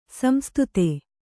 ♪ samstute